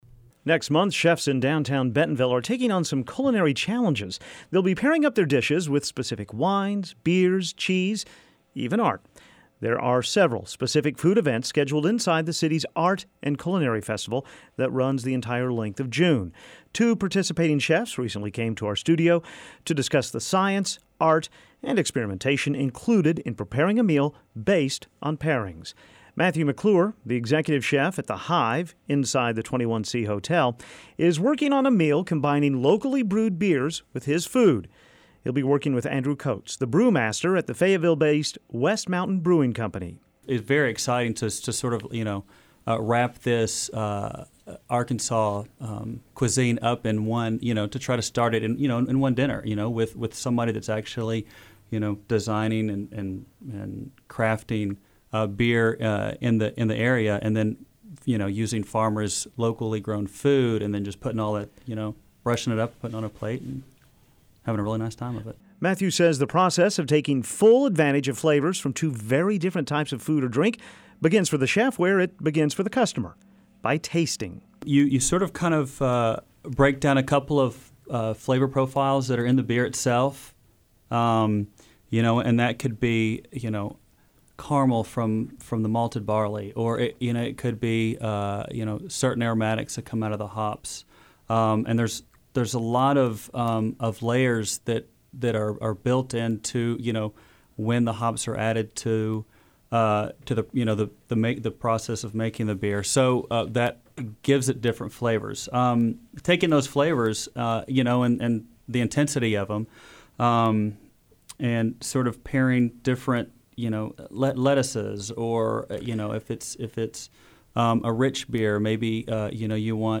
Next month, chefs in Downtown Bentonville will take on the challenge of pairing their dishes with everything from beer and wine to works of art. We speak with two of the participating chefs about the science, art and experimentation that goes along with those pairings.